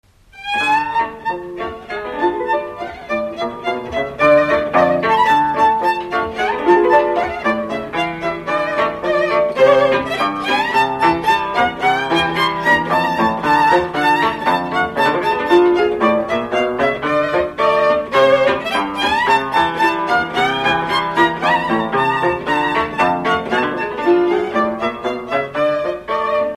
Dallampélda: Hangszeres felvétel
hegedű
cimbalom Műfaj: Söprűtánc Gyűjtő